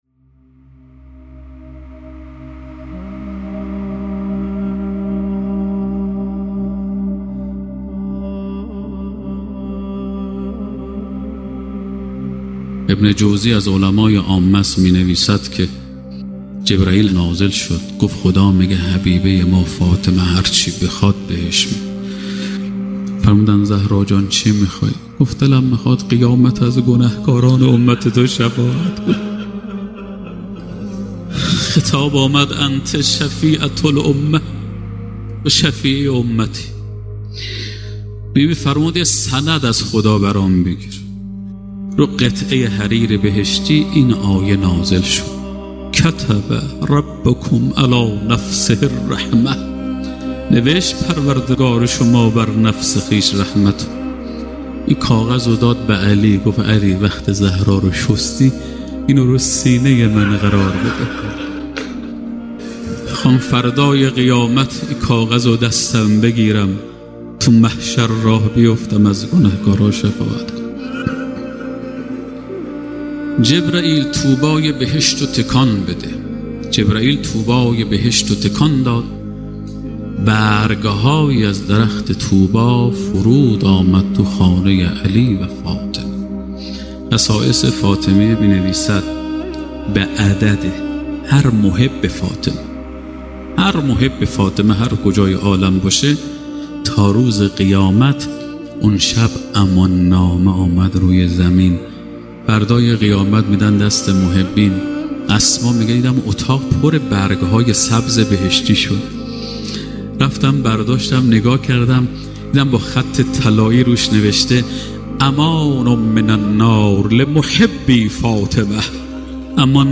ایکنا به مناسبت ایام سوگواری شهادت دخت گرامی آخرین پیام‌آور نور و رحمت، مجموعه‌ای از سخنرانی اساتید اخلاق کشور درباره شهادت ام ابیها (س) با عنوان «ذکر خیر ماه» منتشر می‌کند.